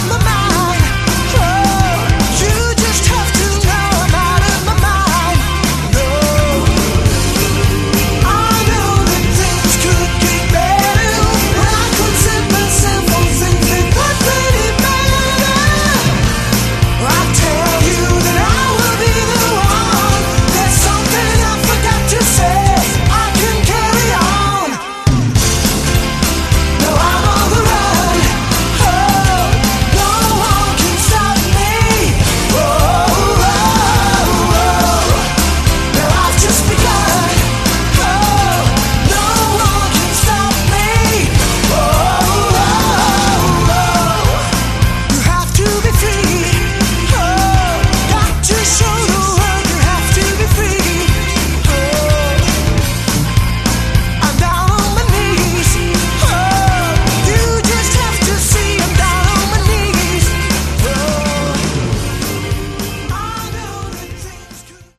Category: AOR
guitar, keyboards, programming
lead and backing vocals
drums